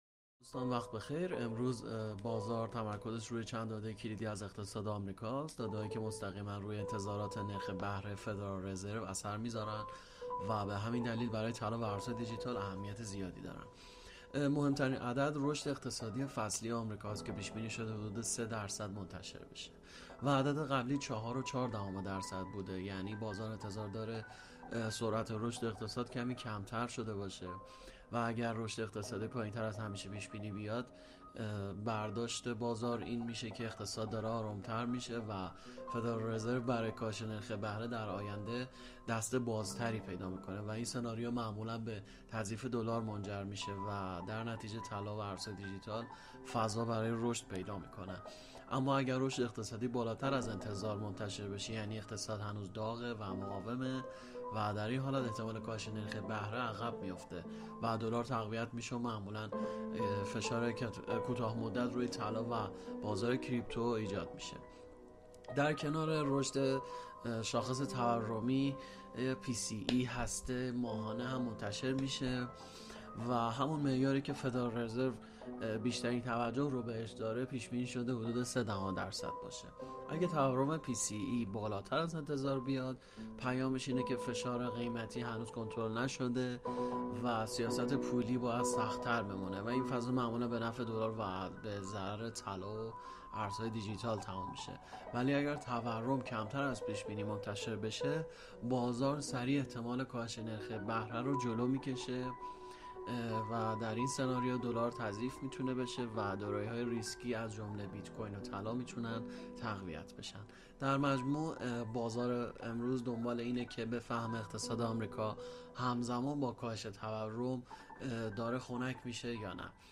رادیو ایگل : تحلیل اخبار اقتصادی شاخص PMI آمریکا (15 اردیبهشت)
🔸گروه مالی و تحلیلی ایگل با تحلیل‌های صوتی روزانه در خدمت شماست!